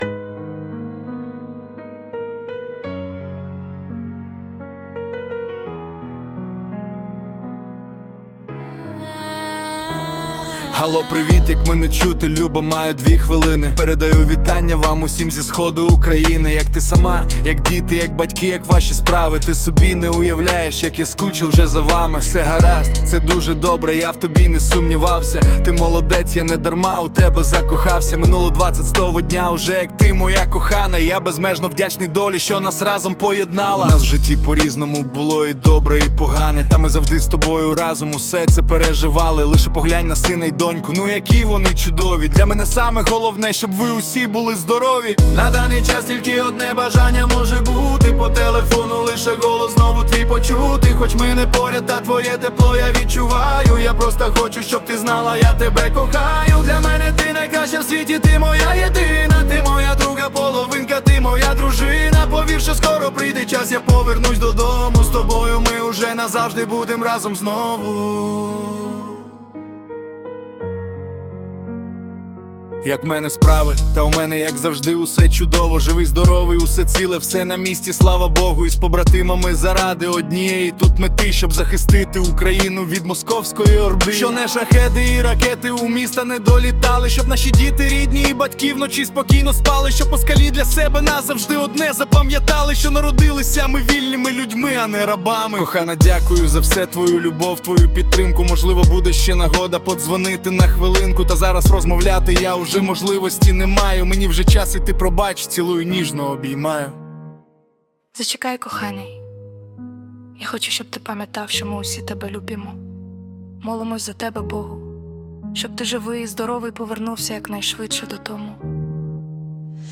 Стиль: Реп, хіп-хоп